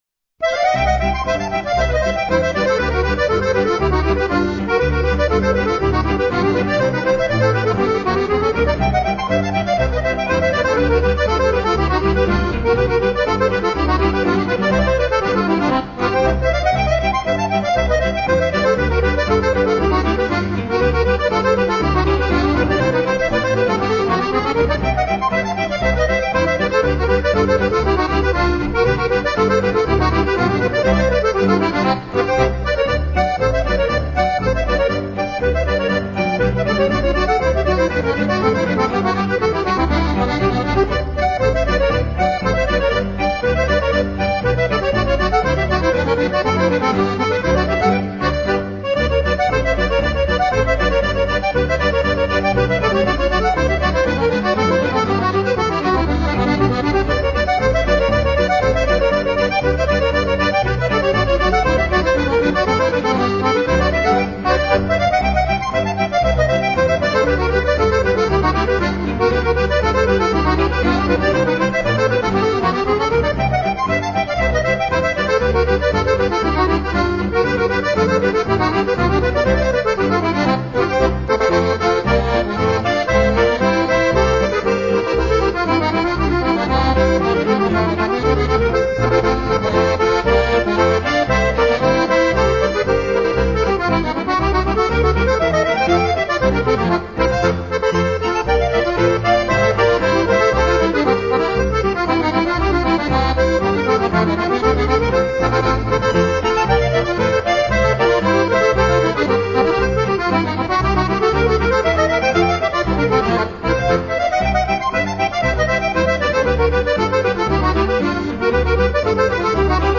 Schottisch